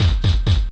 65 MP -BD2-L.wav